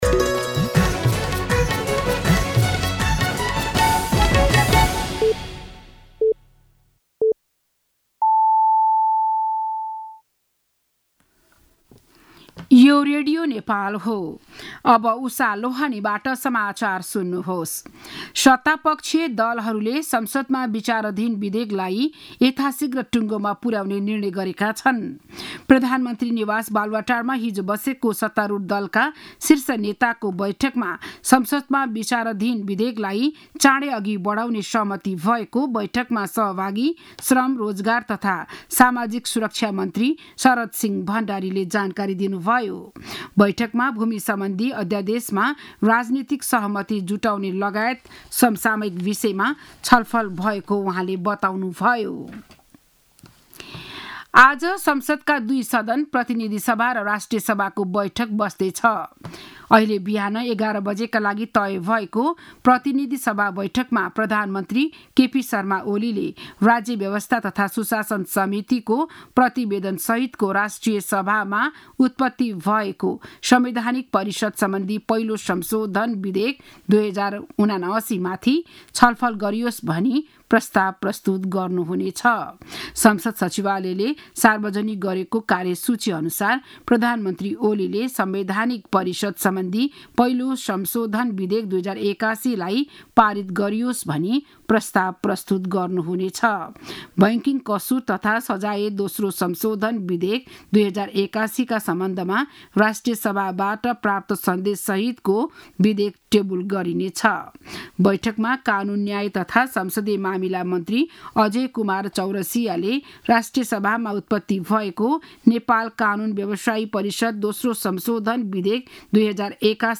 बिहान ११ बजेको नेपाली समाचार : १३ चैत , २०८१
11am-News-13.mp3